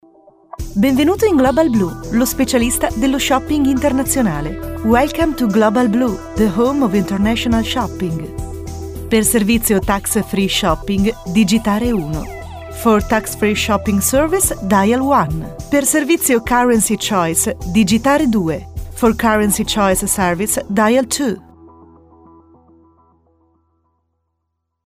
Voce versatile per voiceovers, radio show, pubblicità e messaggi di segreteria telefonica
Sprechprobe: Sonstiges (Muttersprache):
Italian Voice Over Talent & Radio Personality for narrations, advertising, documentaries and phone messages